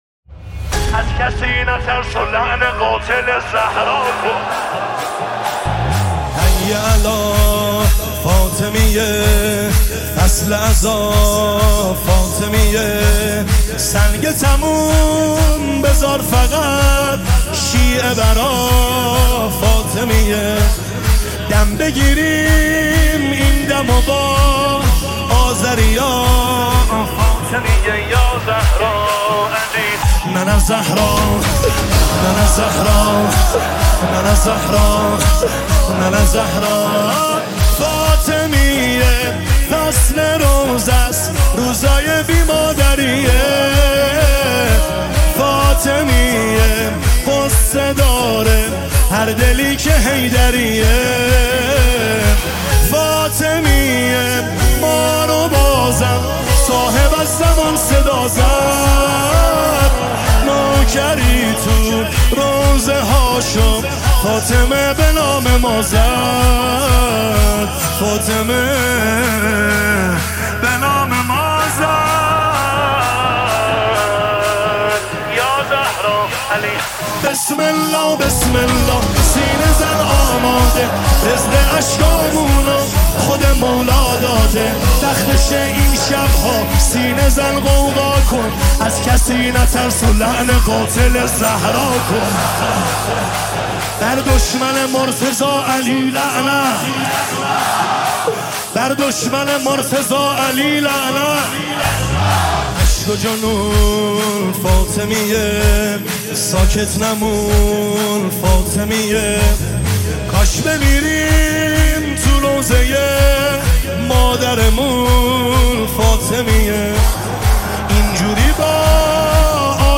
نماهنگ مذهبی مداحی مذهبی